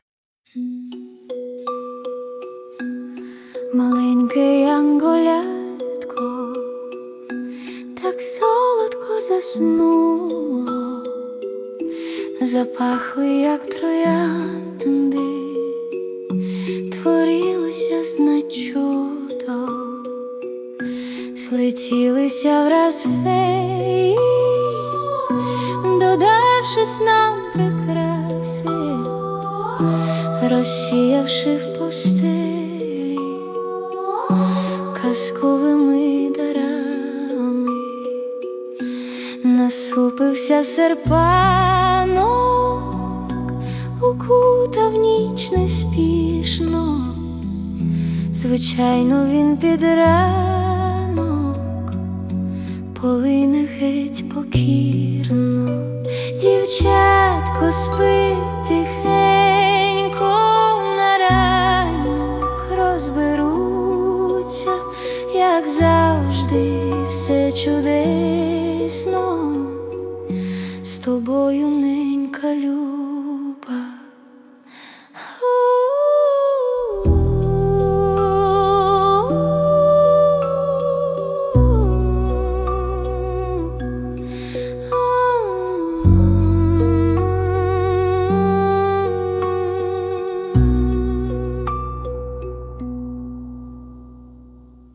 Музична композиція створена за допомогою SUNO AI
Чарівна колискова.
Приємна, заспокійлива мелодія. 39 22 give_rose
чудово! дуже гармонійно і текст.і мелодія з голосом. щасти!